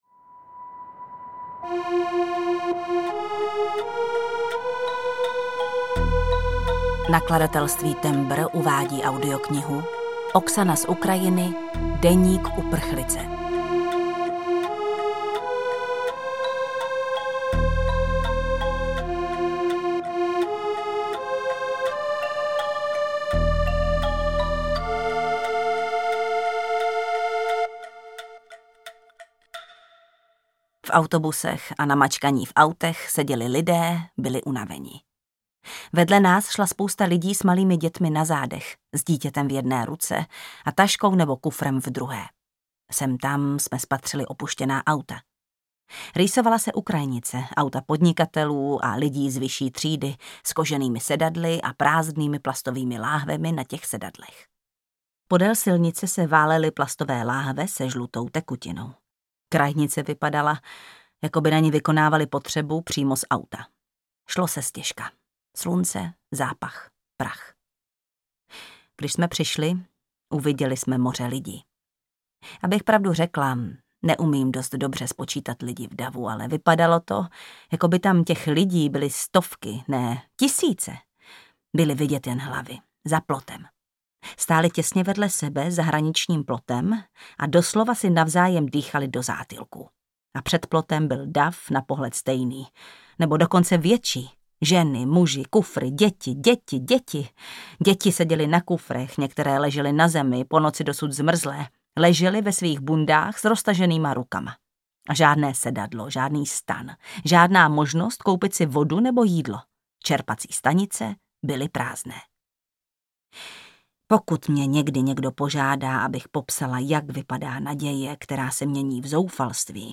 Deník uprchlice audiokniha
Ukázka z knihy
• InterpretJana Stryková